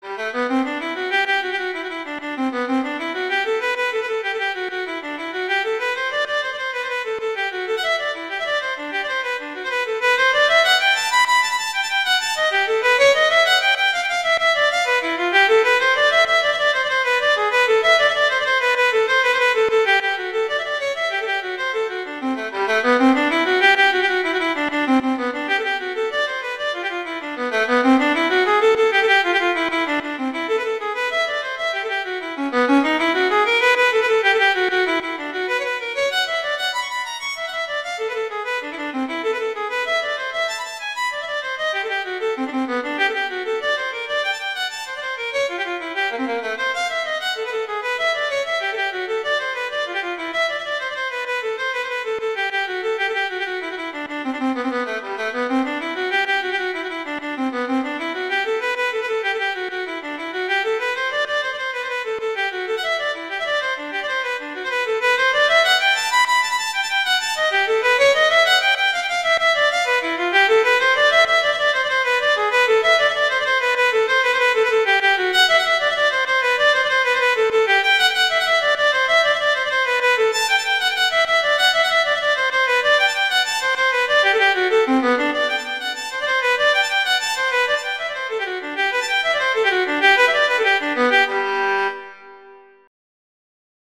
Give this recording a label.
classical, instructional